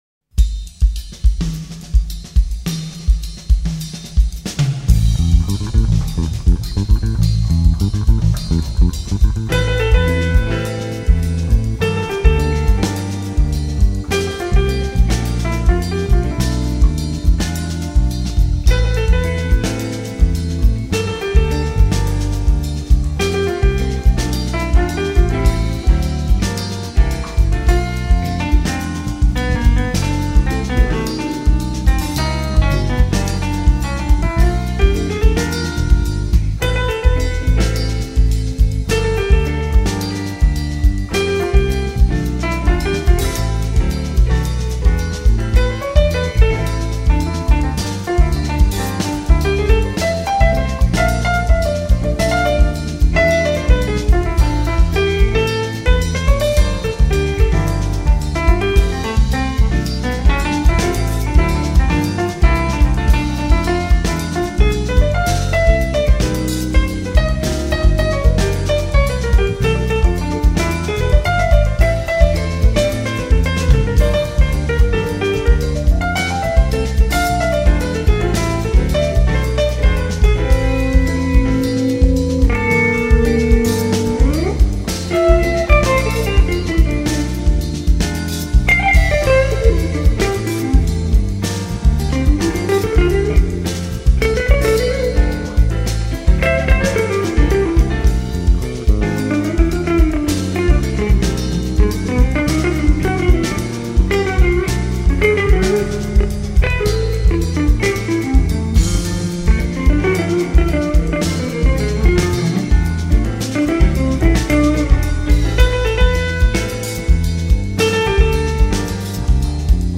557   02:41:00   Faixa: 1    Jazz
Gravado no Teatro 4 de Setembro